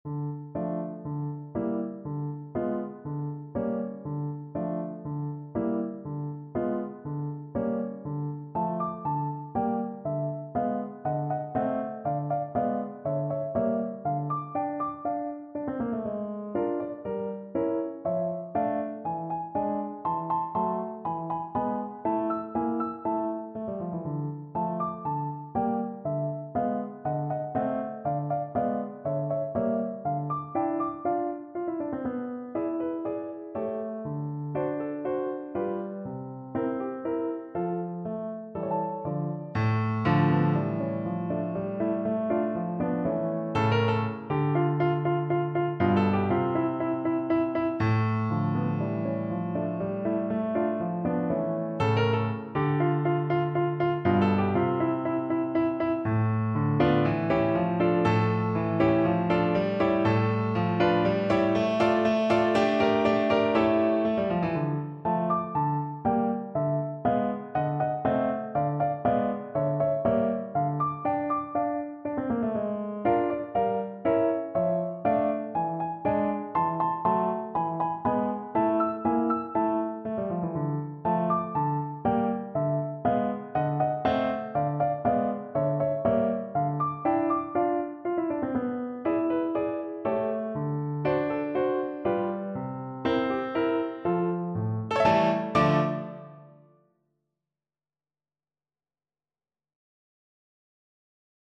Fairly slow and graceful = 120
4/4 (View more 4/4 Music)
Classical (View more Classical Baritone Saxophone Music)